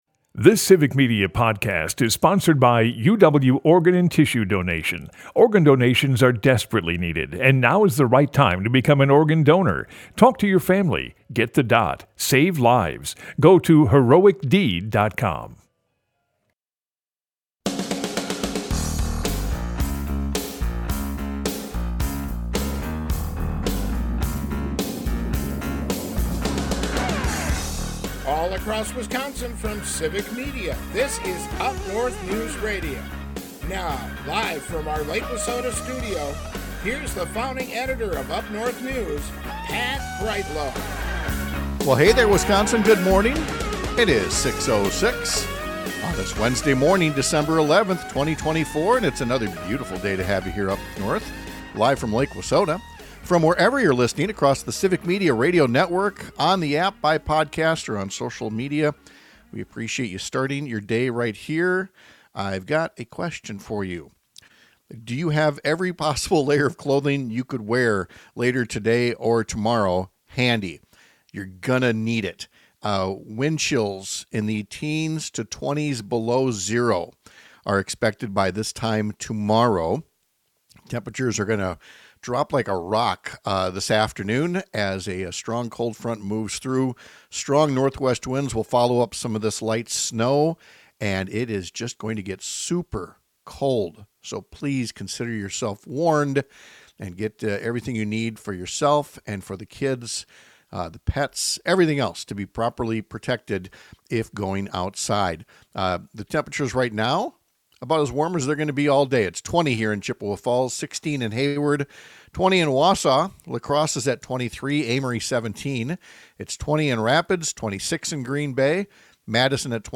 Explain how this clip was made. UpNorthNews is Northern Wisconsin's home for informative stories and fact-based conversations. Broadcasts live 6 - 8 a.m. across the state!